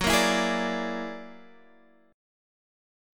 Gb+9 Chord
Listen to Gb+9 strummed